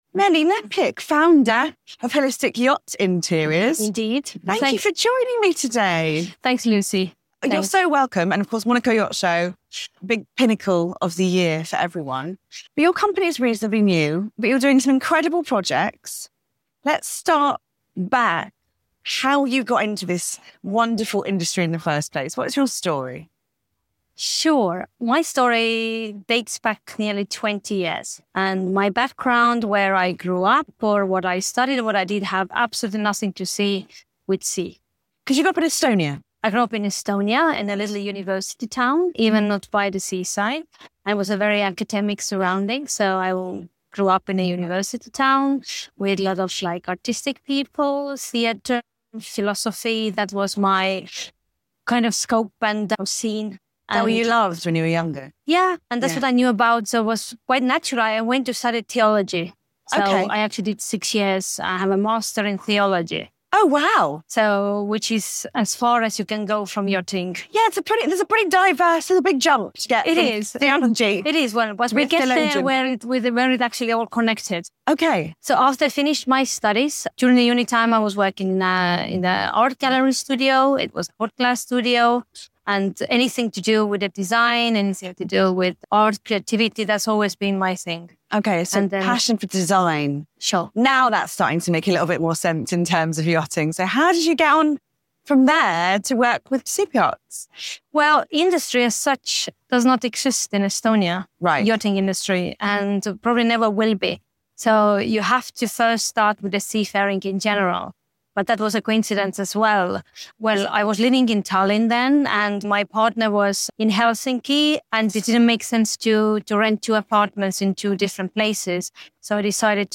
📍 Filmed at the Monaco Yacht Show 2025